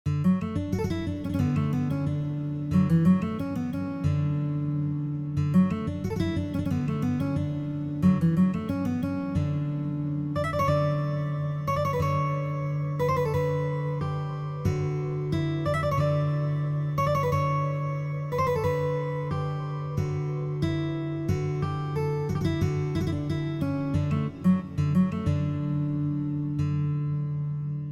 The arrangements use both standard and drop-D tunings.
solo guitar MP3